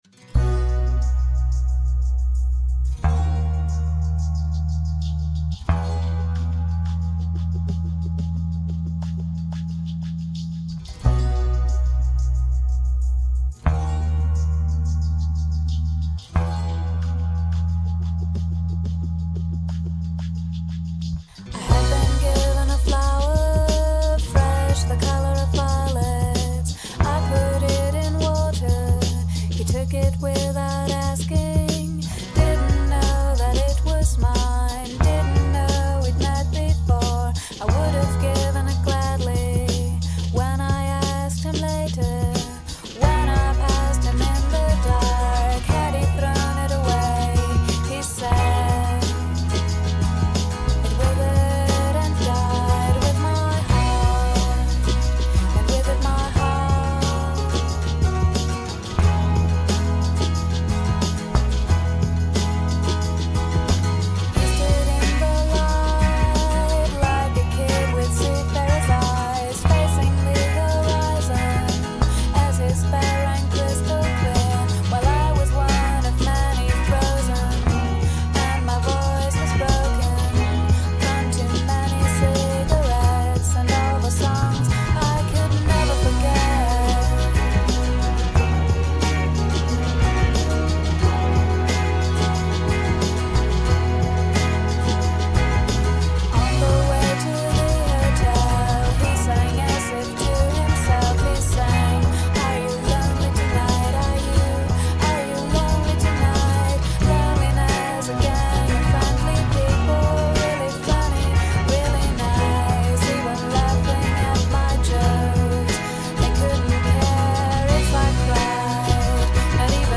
harmonica
DISCO MIX